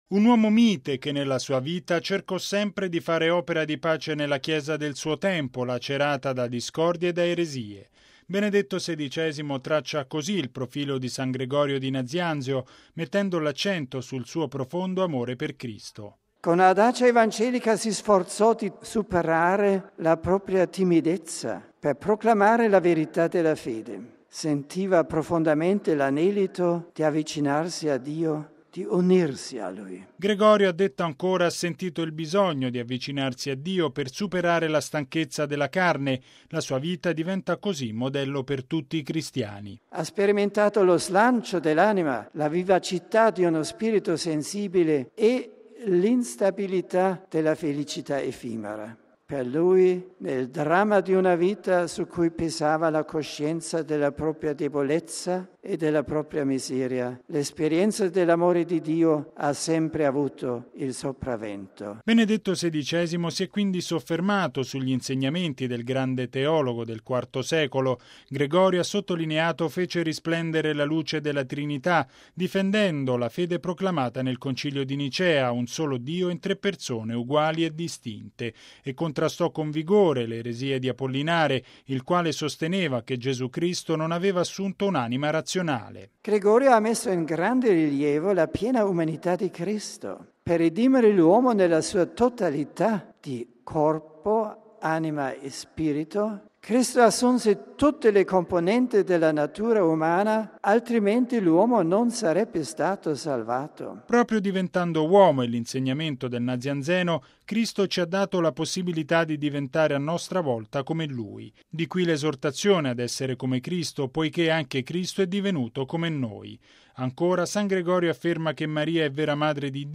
◊   San Gregorio Nazianzeno ci insegna che l’amore di Dio ha sempre il sopravvento sulle nostre debolezze: è la riflessione offerta, stamani, da Benedetto XVI ai fedeli, raccolti in Aula Paolo VI per l’udienza generale.
Particolarmente festoso il momento dei saluti ai pellegrini italiani.